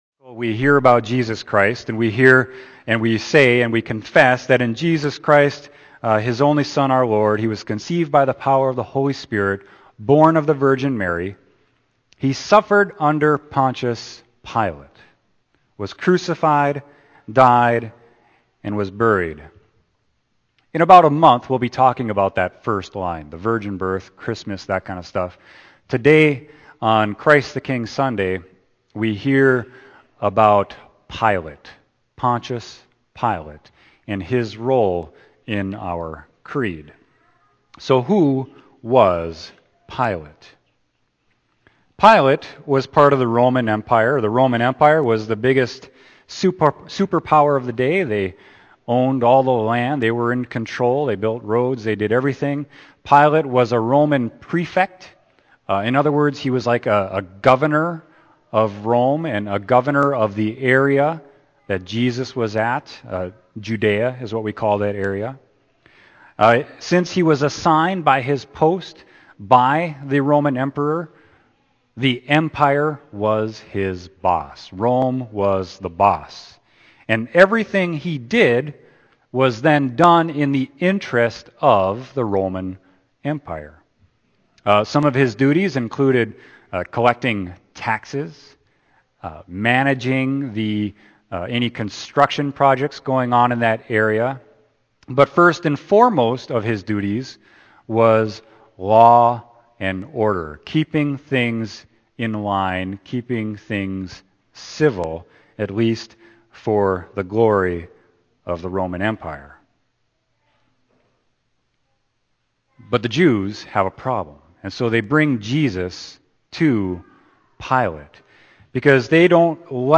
Sermon: John 18.33-37